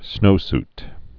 (snōst)